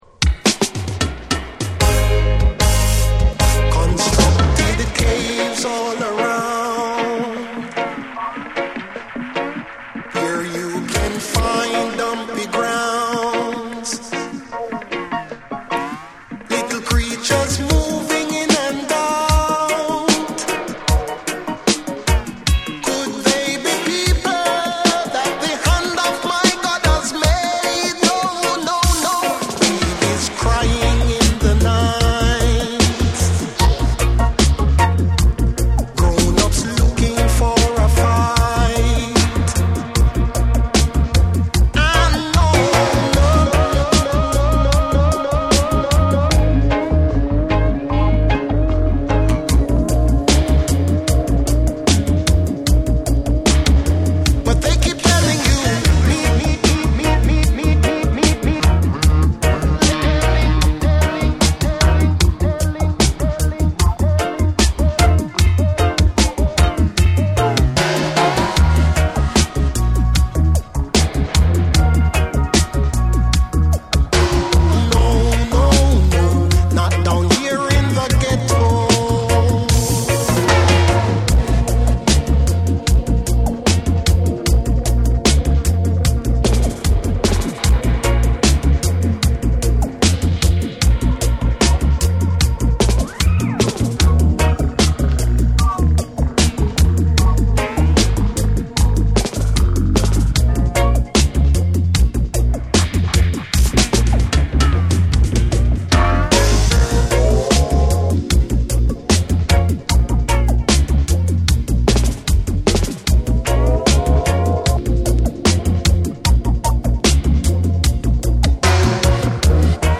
REGGAE & DUB